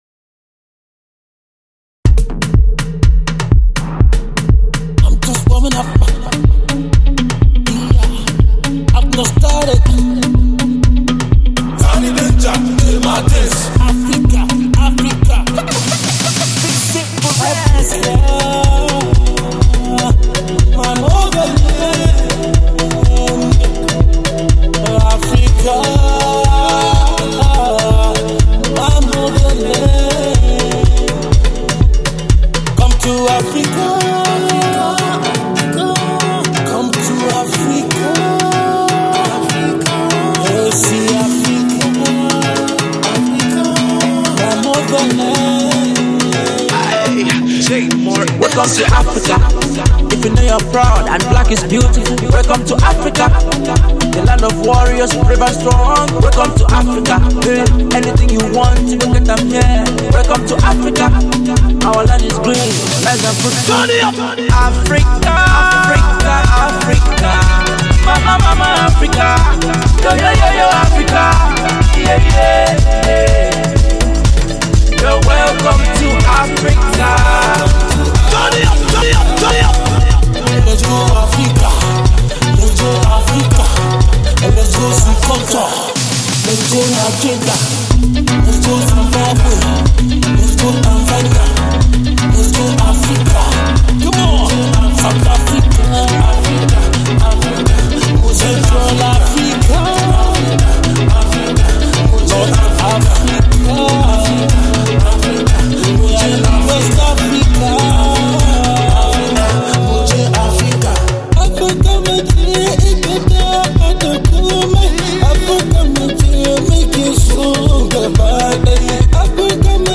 Hausa Music, Pop